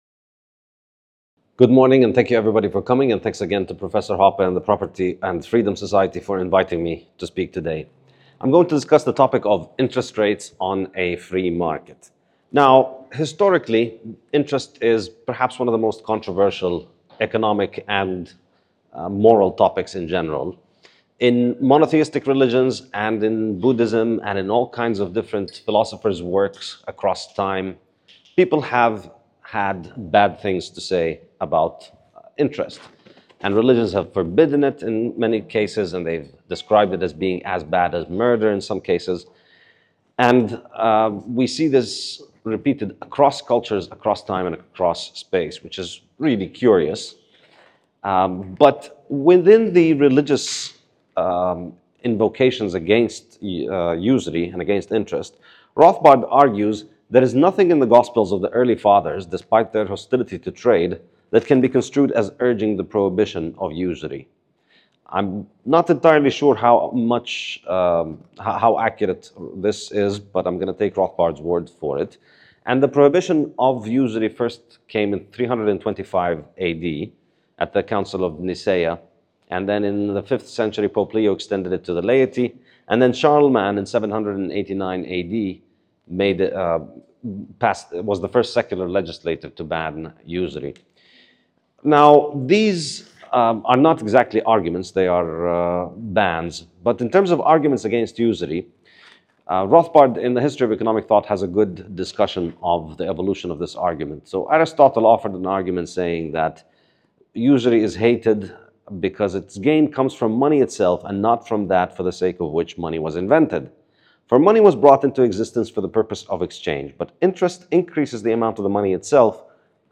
This talk is from the 18th annual 2024 Annual Meeting of the PFS (Sept. 19–24, 2024, Bodrum, Turkey).